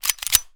gun_pistol_cock_07.wav